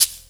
50PERC01  -R.wav